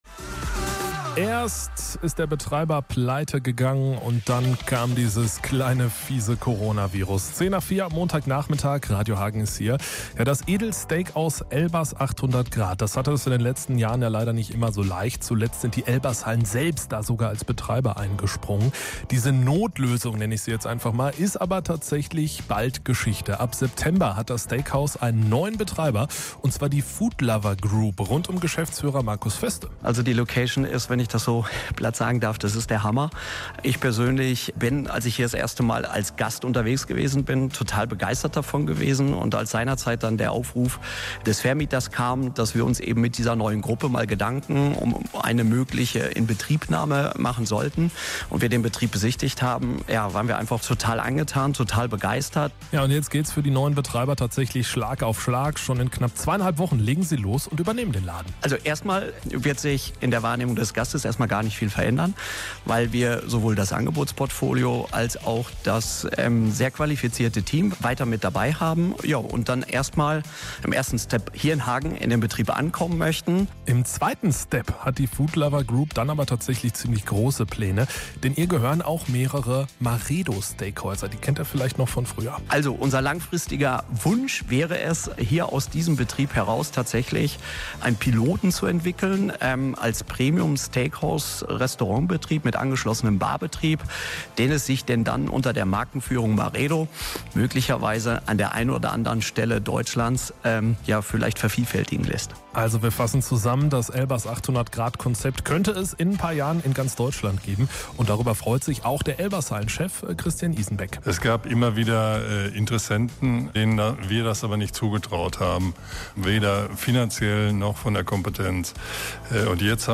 Die neuen Betreiber haben aber große und langfristige Pläne. Hier gibt es den Sendungsmitschnitt zum Nachhören.